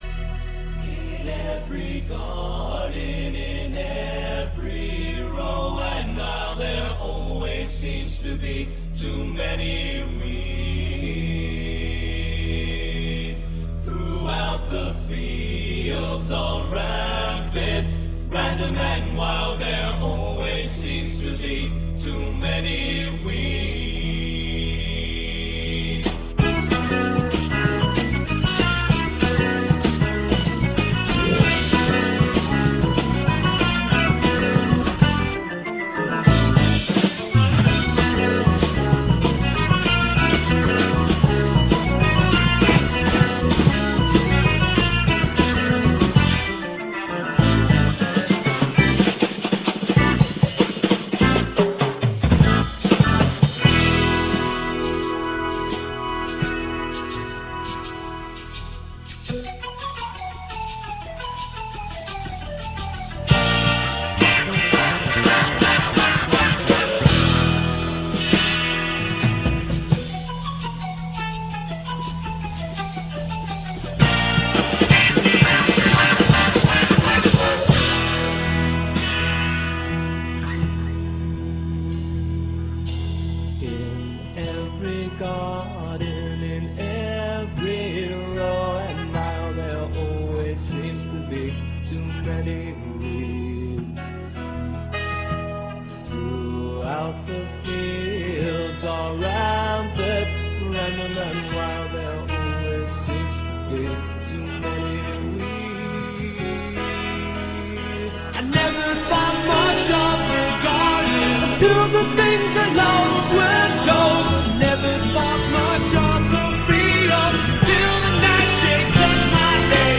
Biblioteca Sonora de Rock Progressivo